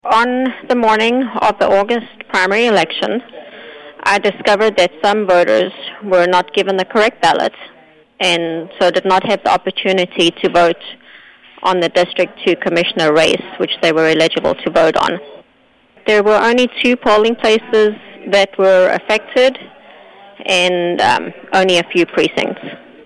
County Clerk Petal Stanley explains to KMMO News what led to the court order calling for a new election.